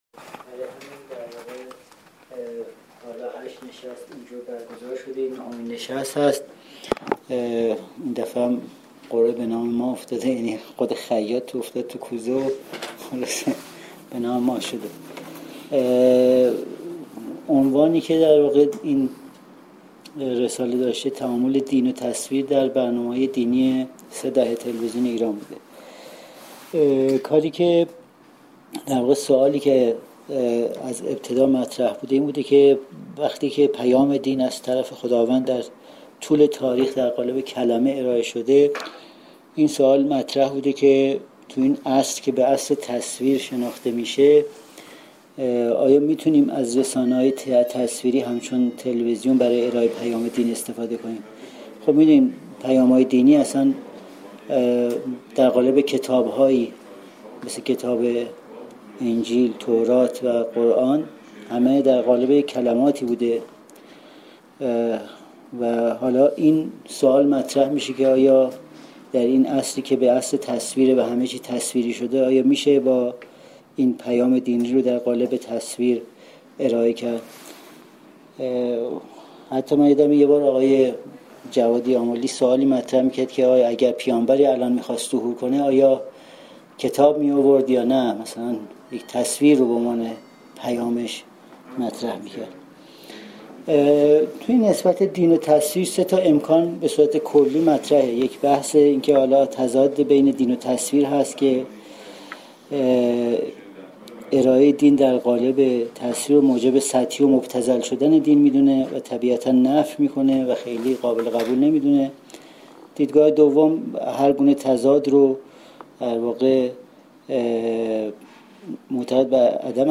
سالن اندیشه